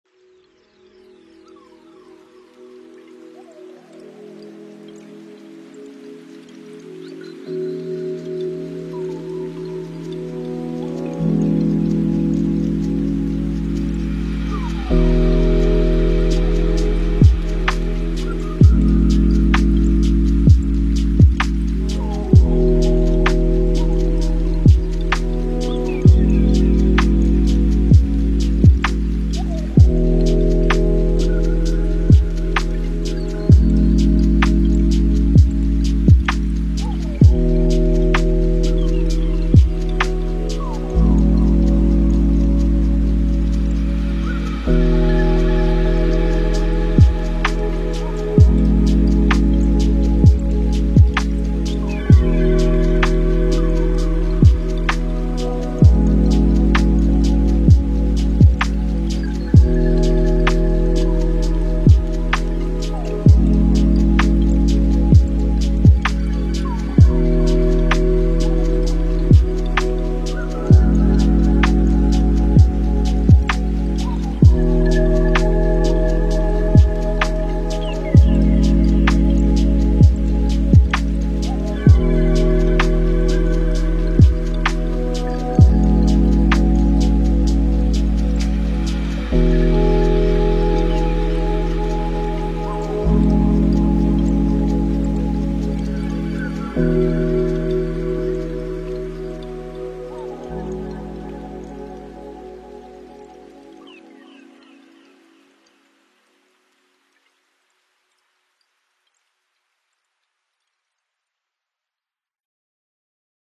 Мы подобрали для вас лучшие lo-fi песни без слов.
Спокойная мелодия 🌿